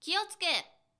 ダウンロード 女性_「よろしくっ」
キュート挨拶